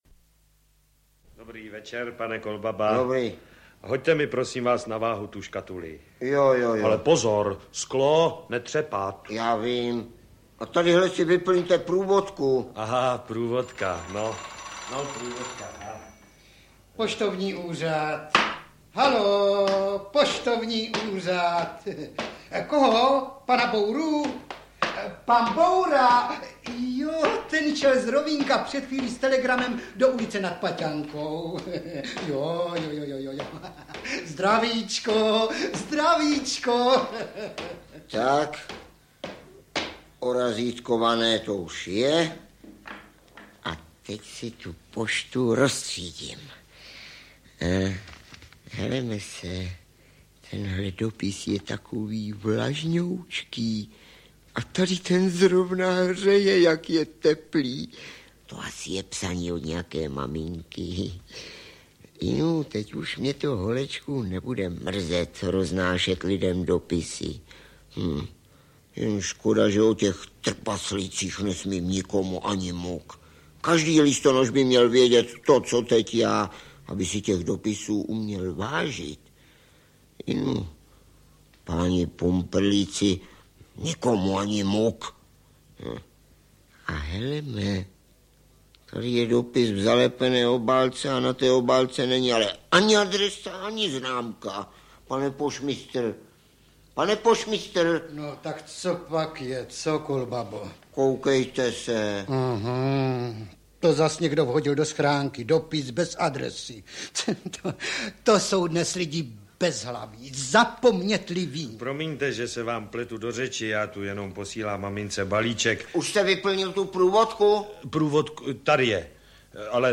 Pohádka pošťácká audiokniha
pohadka-postacka-audiokniha